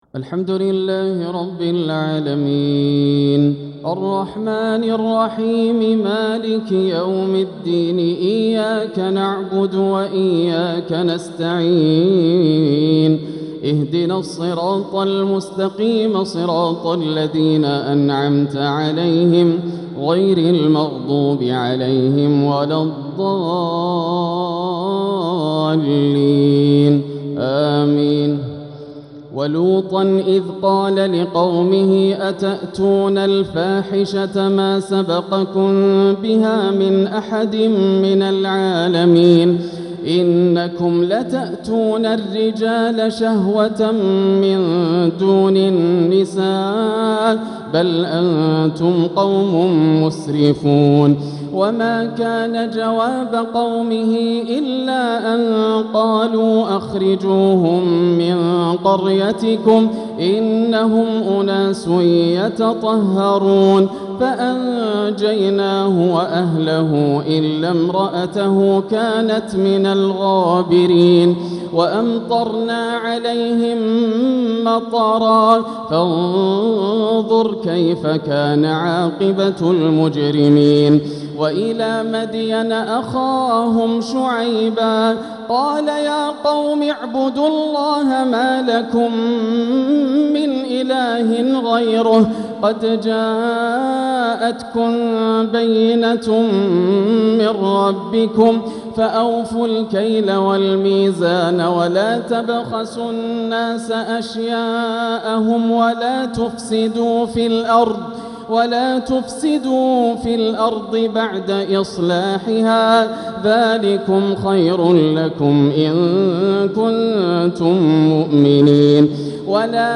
تراويح ليلة 11 رمضان 1446هـ من سورة الأعراف (80-141) > الليالي الكاملة > رمضان 1446 هـ > التراويح - تلاوات ياسر الدوسري